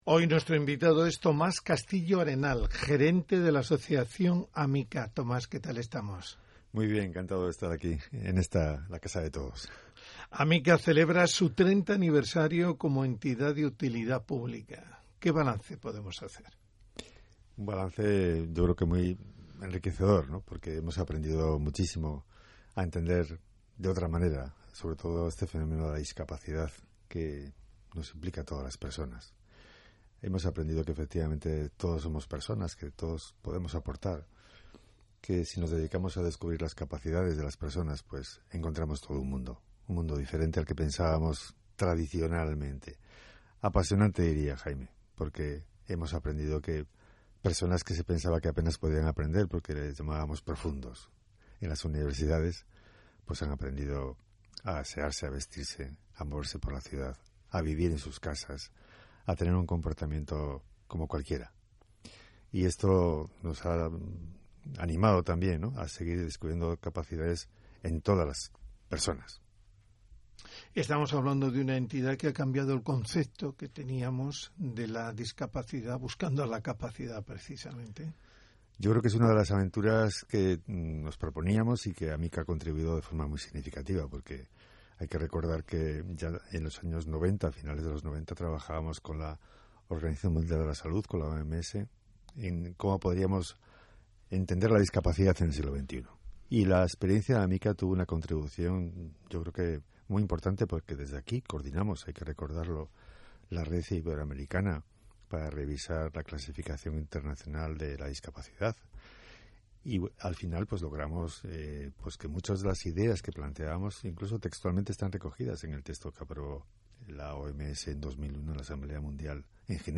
Repasamos la actualidad y nuestra historia en una entrevista en RNE Cantabria